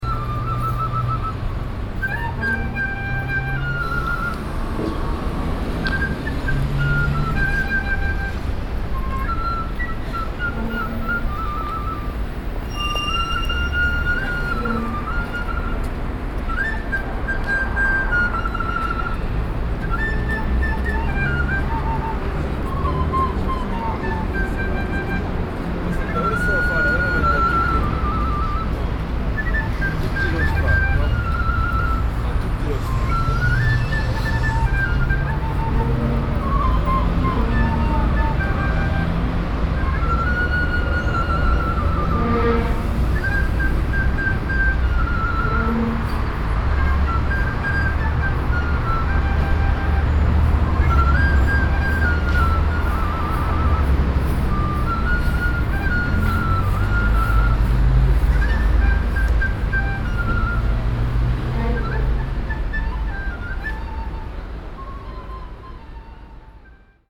Rumore
Un suonatore di flauto sul sottofondo del traffico cittadino Torino, Piazza XVIII Dicembre
Microfoni binaurali stereo SOUNDMAN OKM II-K / Registratore ZOOM H4n
Flauto.mp3